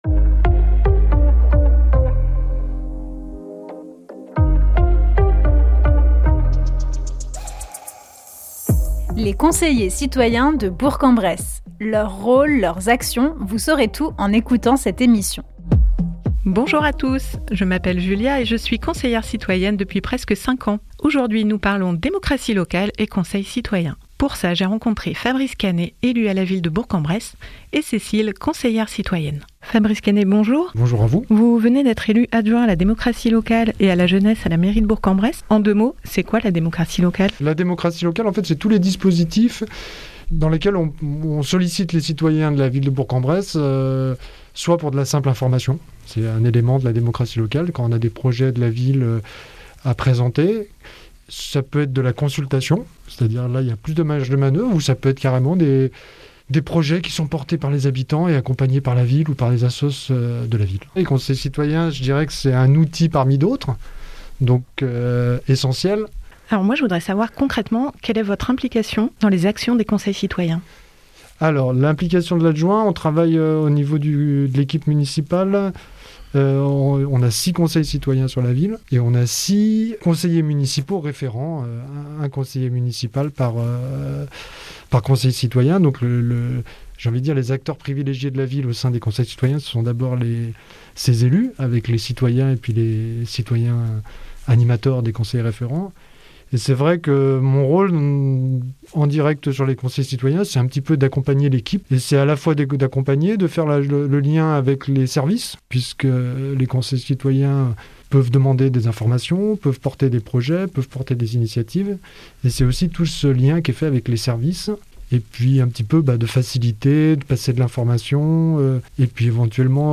Présentée par : Des conseillers citoyens.